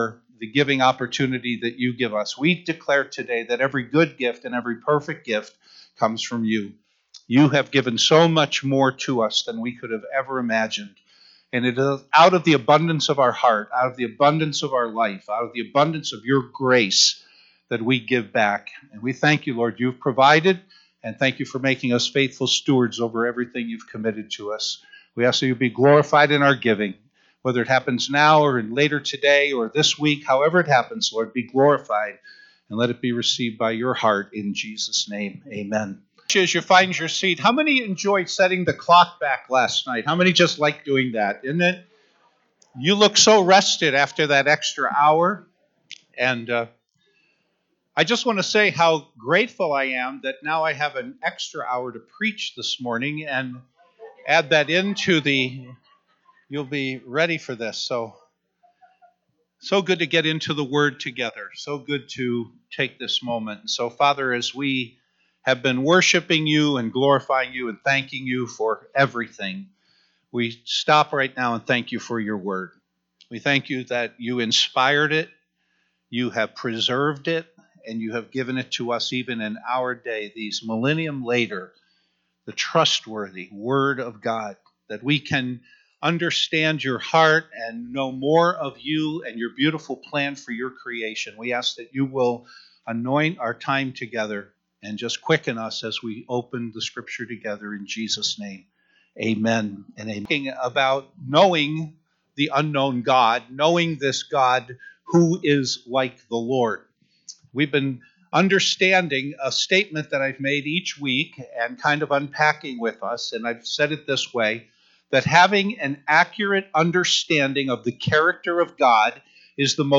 Messages preached in the year 2021